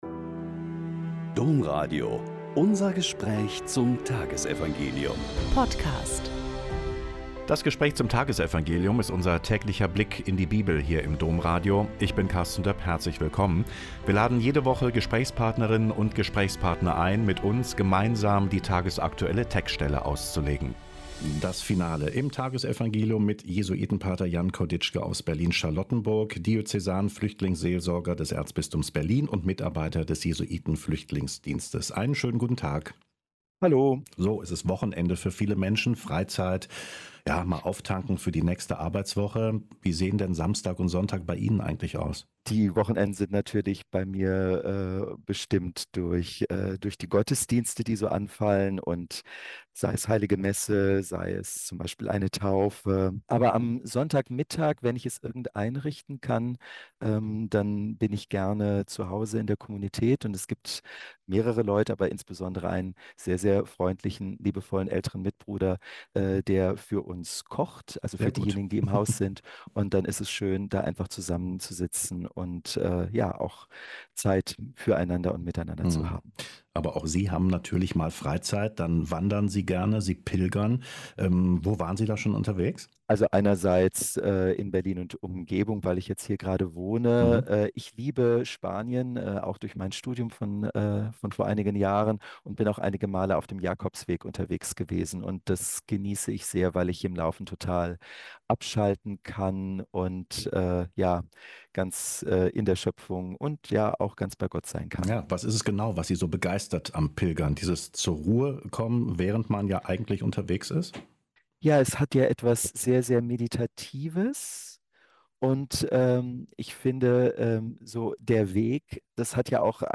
Mt 16,13-19 - Gespräch